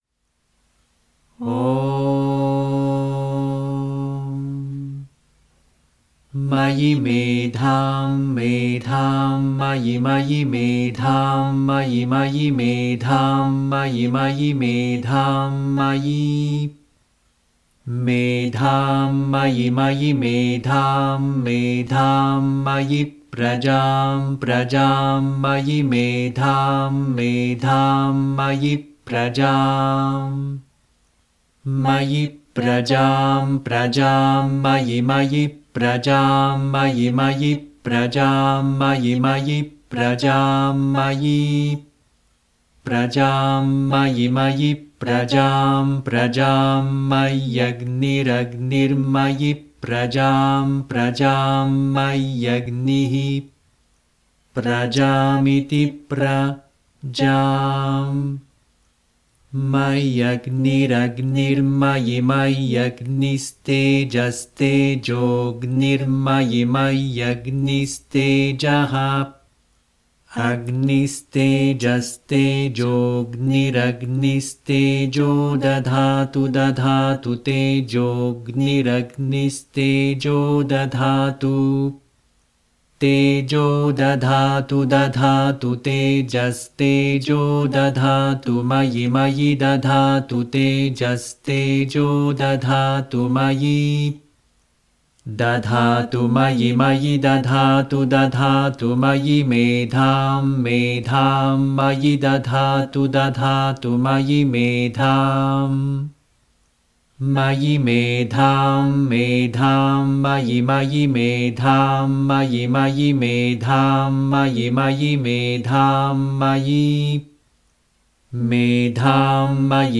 mayi medhaam ghana paaThaH - chant.mp3